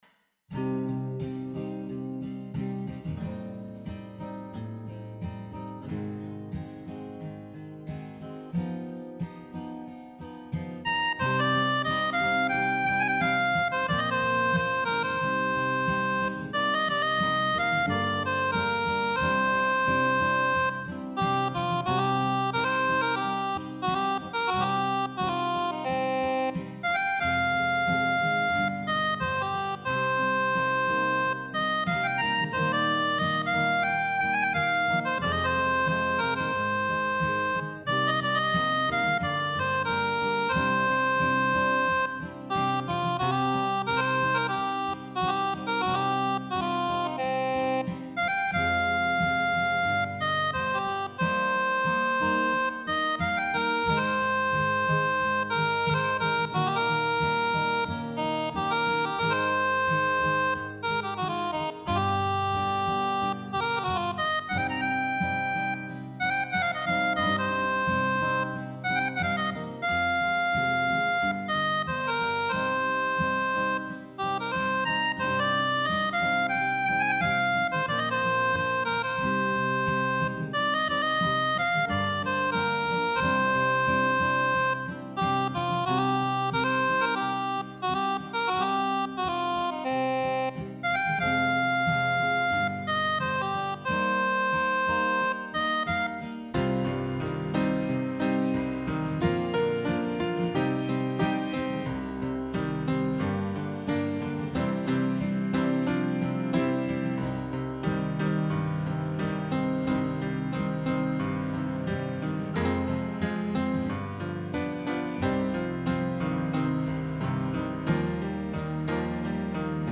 哀愁漂うカントリーバラードが出来ました。
カントリー
最初に意図したブルースとは掛け離れて、気が付けば、哀愁漂うカントリー調の曲が出来ていました。
ハーモニカと生ギターが、なかなかいい味出しています。